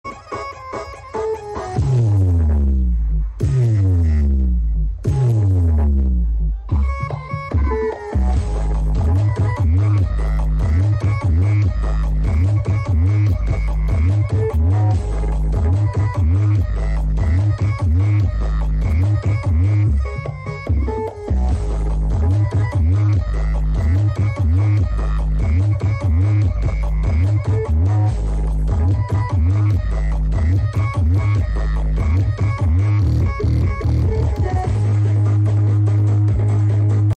Bass nyembor 😩 sound effects free download